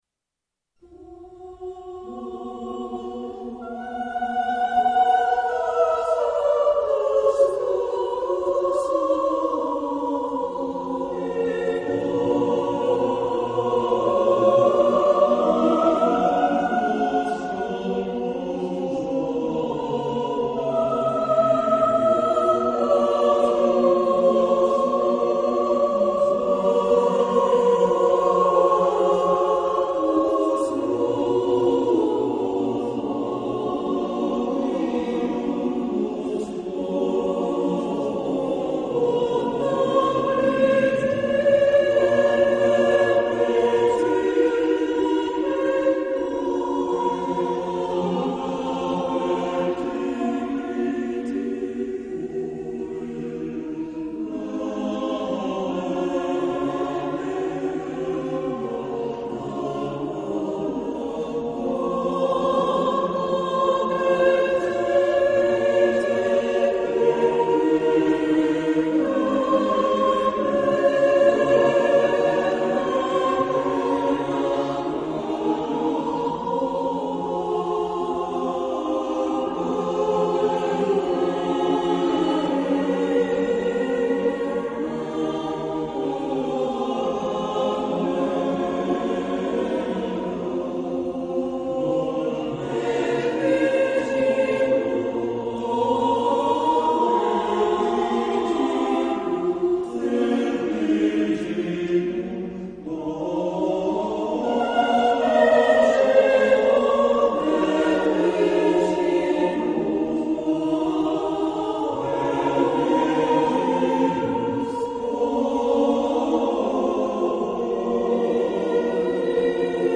O quantus luctus da Motecta festorum totius anni cum communi sanctorum quaternis vocibus (Roma 1563)
Coro Polifónico de Resistencia (Argentina); 4'10"